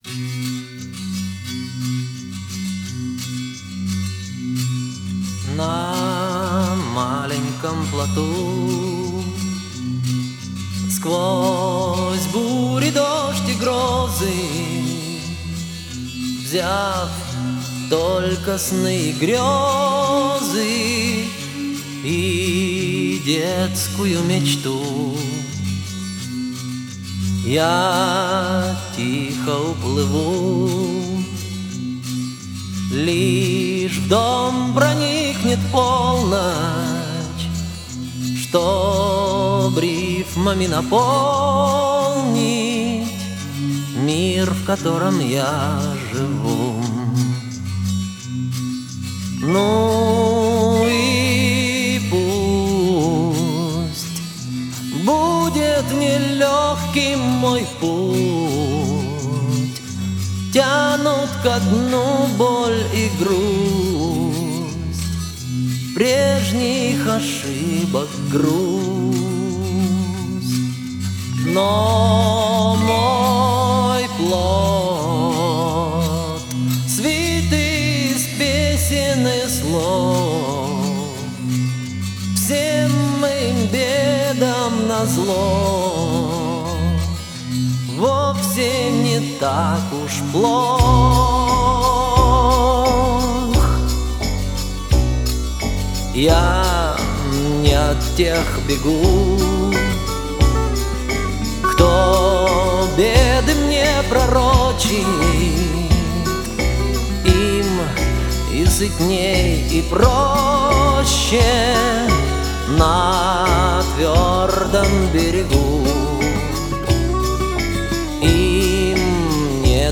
Есть запись почище.